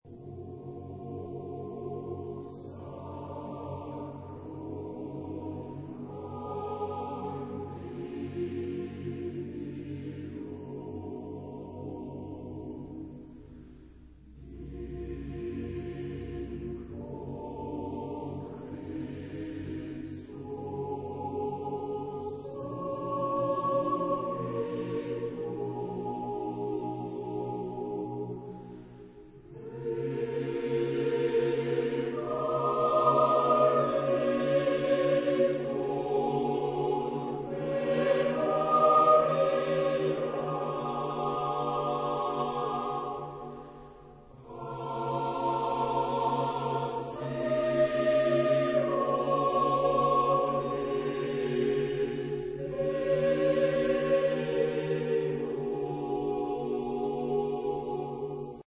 Genre-Style-Forme : Sacré
Type de choeur : SATB  (4 voix mixtes )
Instrumentation : Orgue